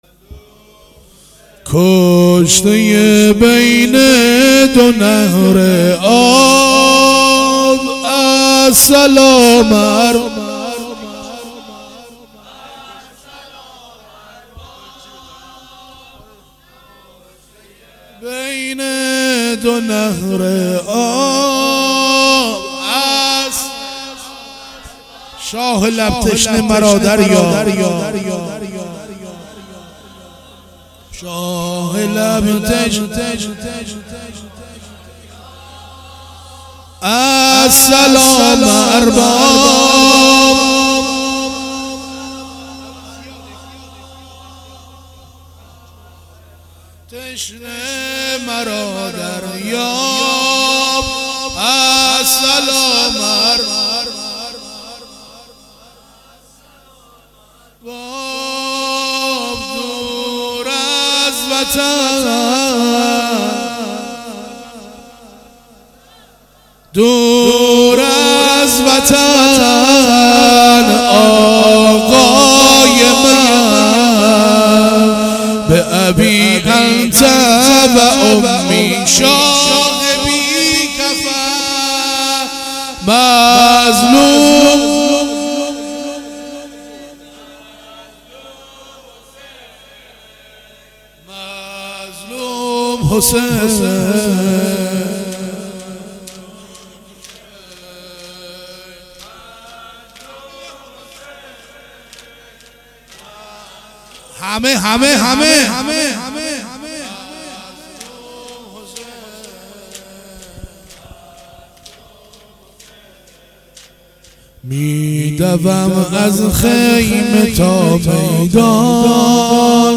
زمینه شب پنجم محرم 96